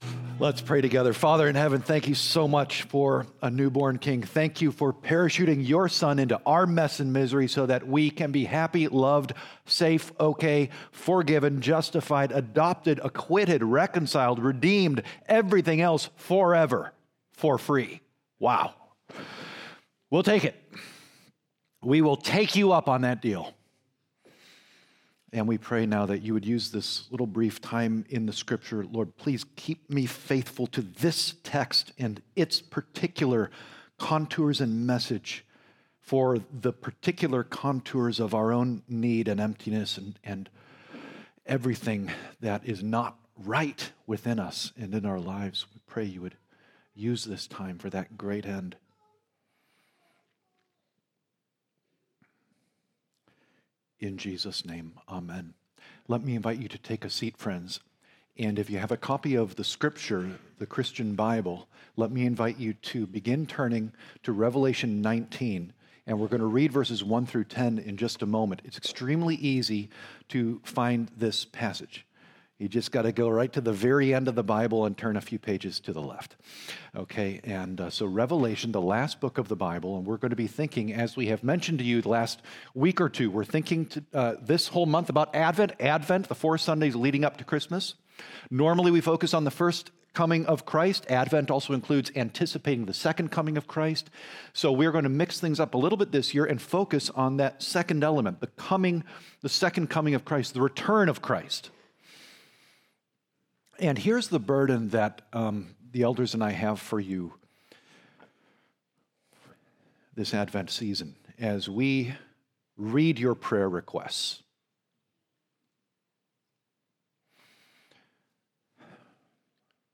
Naperville Presbyterian Church Sermons Podcast - Revelation 19:1-10 | Free Listening on Podbean App